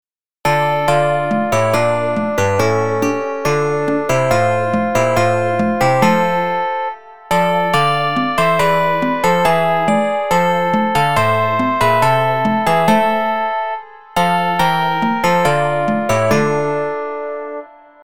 for blandakor (song og piano) 1977